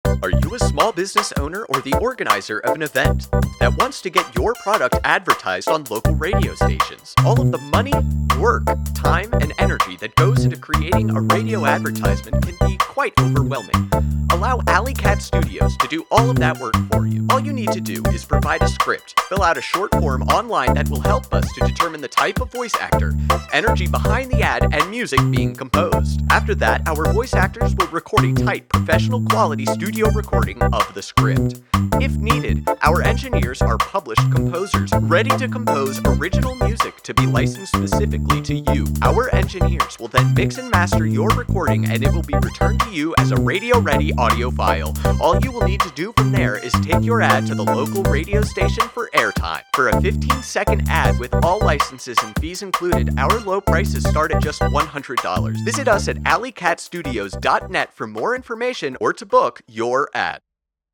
Radio Advertisement - Full Production Services
After that, our voice actors will record a tight, professional quality, studio recording of the script.